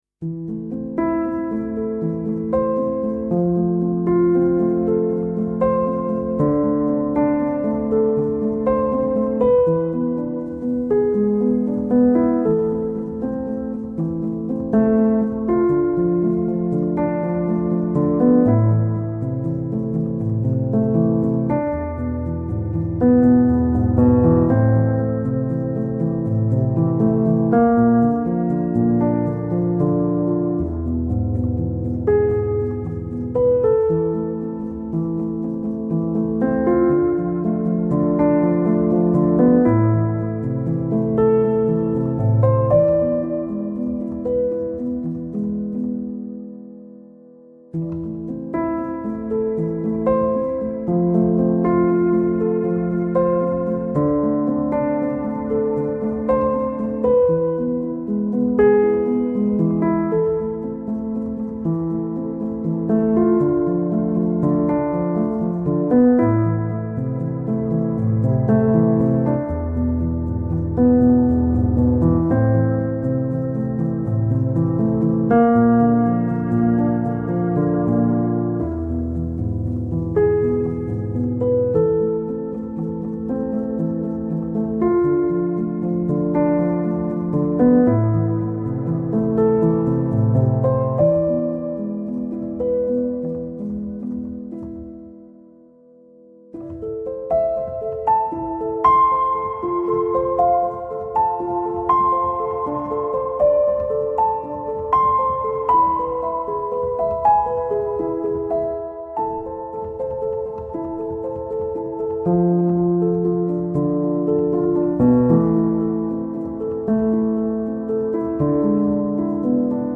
آرامش بخش , پیانو , مدرن کلاسیک , موسیقی بی کلام